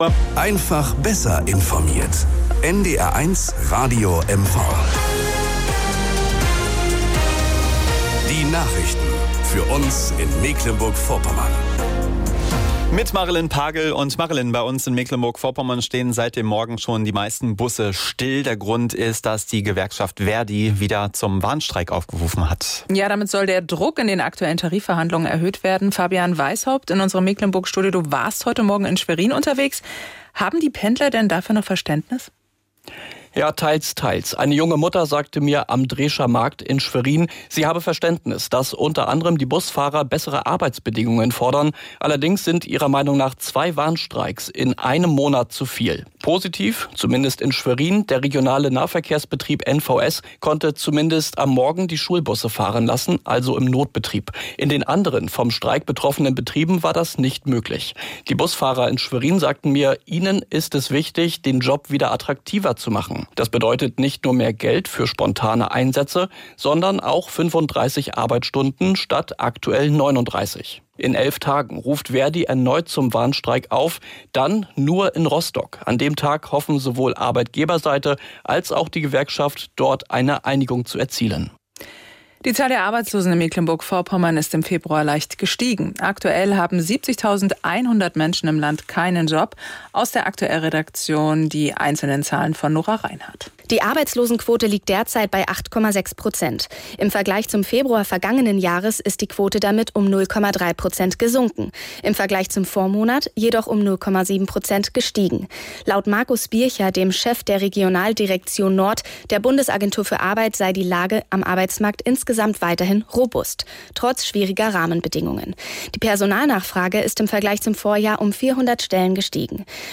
Nachrichten und Informationen aus Mecklenburg-Vorpommern, Deutschland und der Welt von NDR 1 Radio MV.